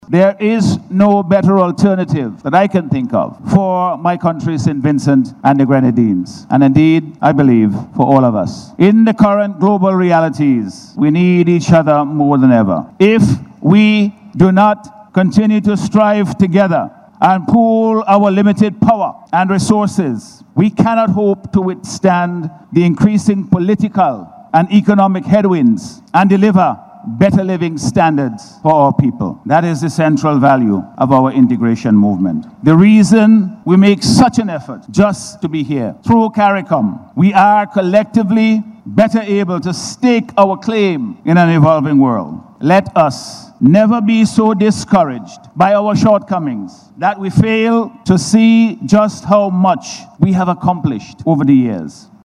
Dr. Friday was addressing regional leaders at the recently concluded 50th Regular Meeting of CARICOM Heads of Government.